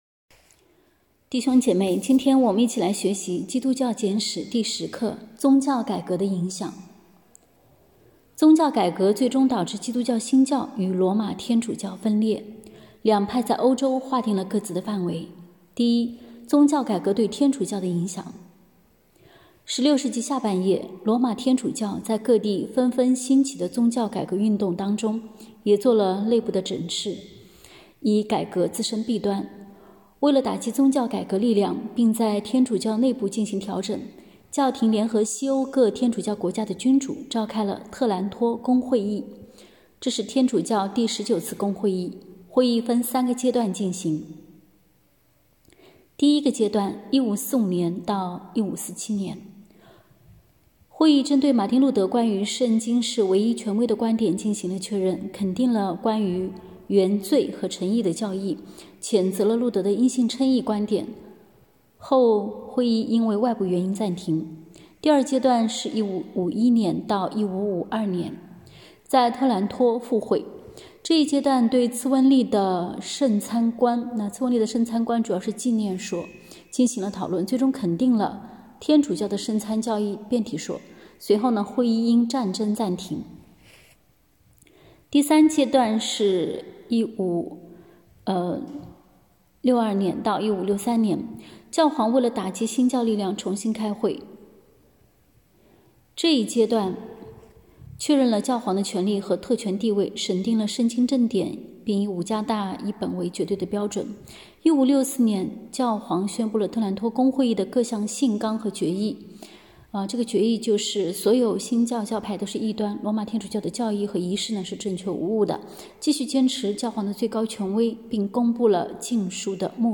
课程音频：